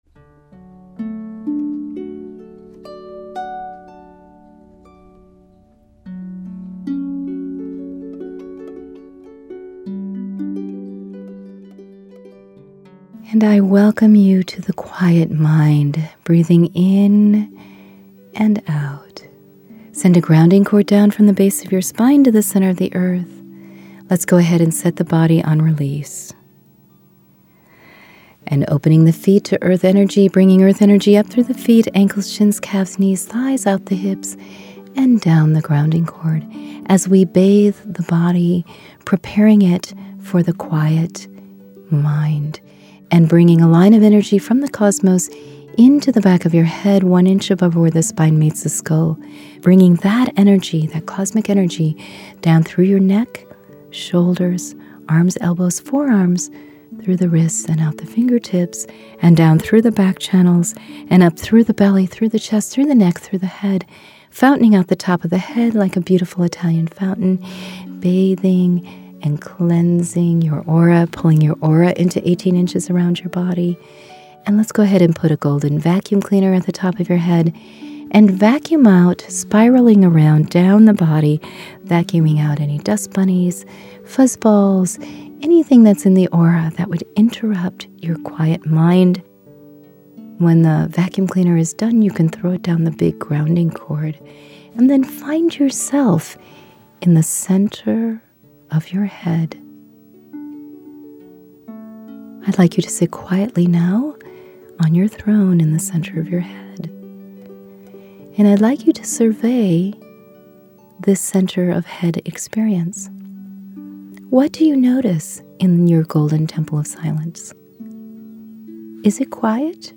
The Q5 Quiet Mind Meditation